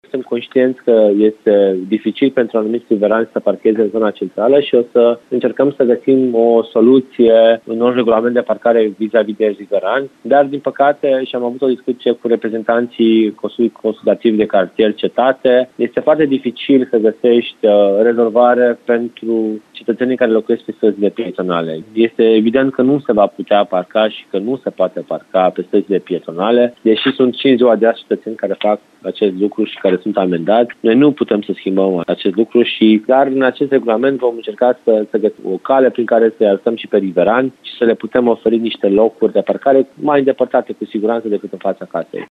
Municipalitatea are în lucru un nou regulament cu privire la politica parcărilor la nivel de municipiu, iar documentul ar trebui să fie gata până în luna iunie, spune viceprimarul Timișoarei, Ruben Lațcău.